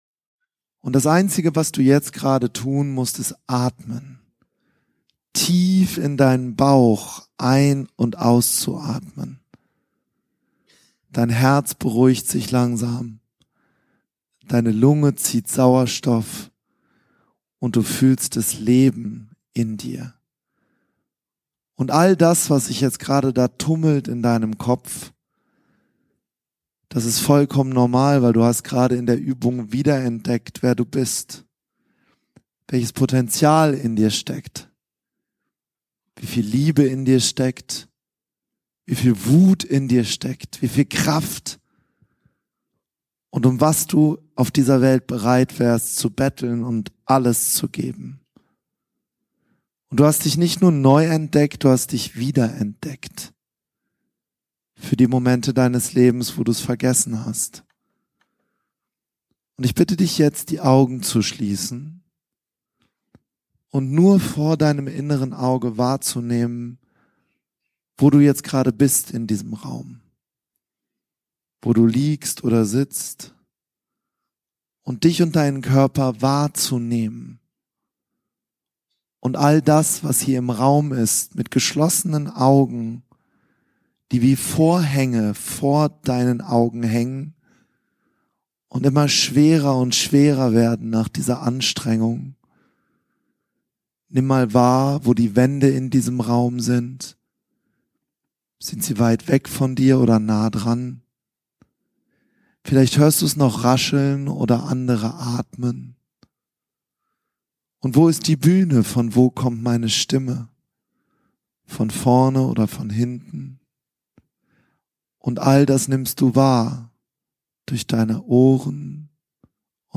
Traumreise-Du-bist-genug-Reise-zu-Deinem-inneren-Kind-Live-Mitschnitt.mp3